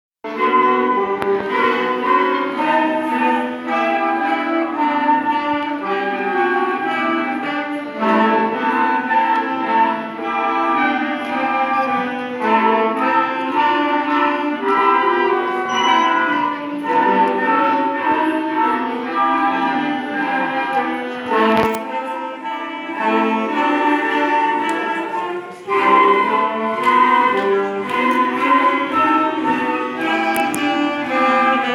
Classes orchestres : les concerts de fin d’année
Deux concerts ont été donnés, à l’occasion de la fête de la musique, à la salle des fêtes de Vandœuvre :
concert_nations.mp3